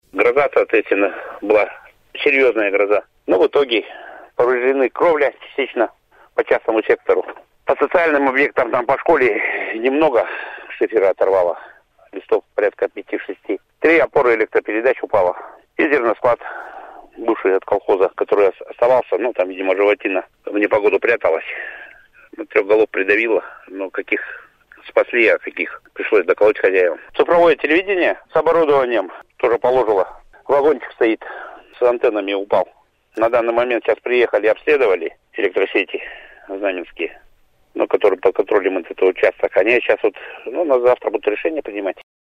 Комментарий главы СП “Илимское” Михаила Путинцева данный программе “Вести-Чита”